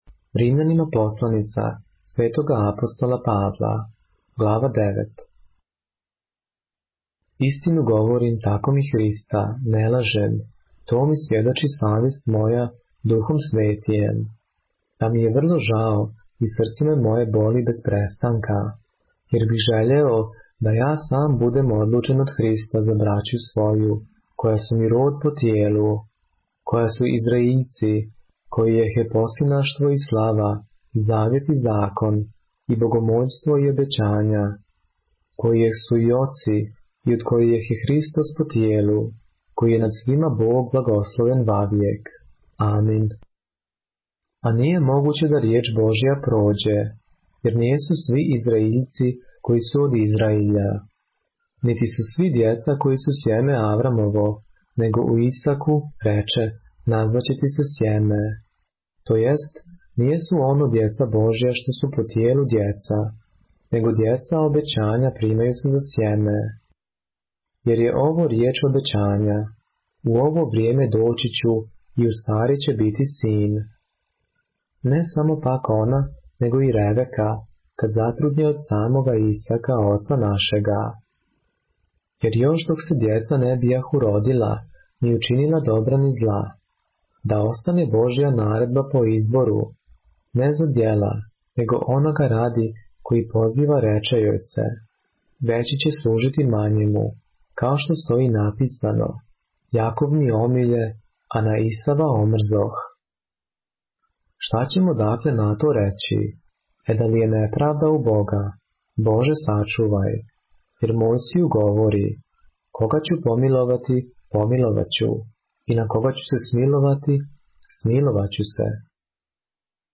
поглавље српске Библије - са аудио нарације - Romans, chapter 9 of the Holy Bible in the Serbian language